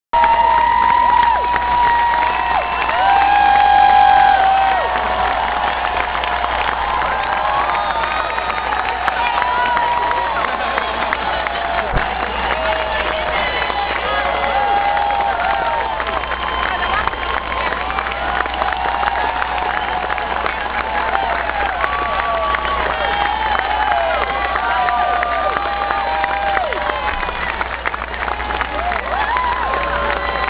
on monday, 17 may 2004, at 12:01am at Cambridge City Hall, Massachusetts became the first state to grant marriage licenses to same-sex couples!
the crowd was jubilant
the crowd reacts as couple #1 emerges (sound clip)
crowd_reacts.WAV